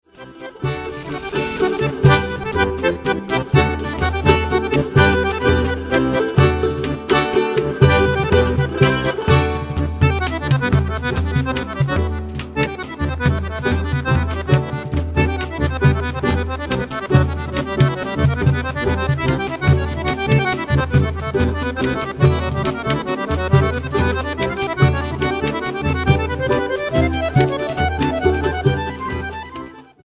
acordeón clásico